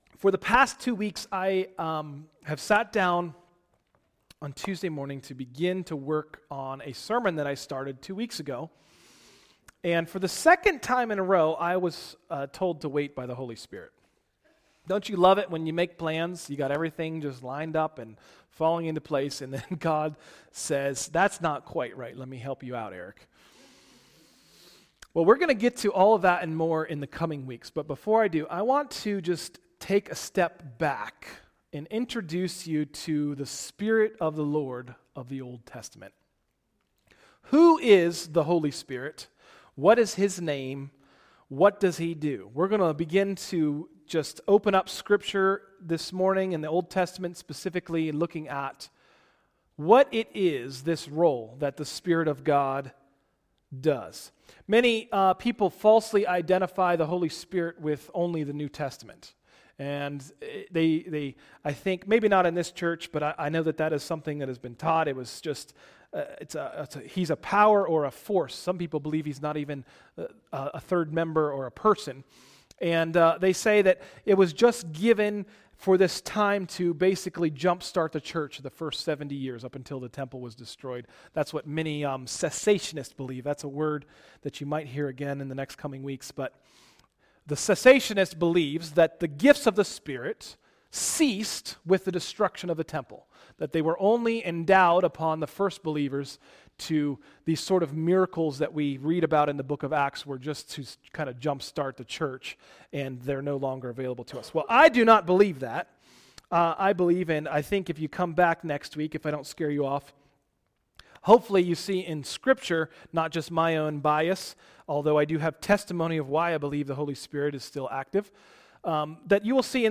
Sermons – Tried Stone Christian Center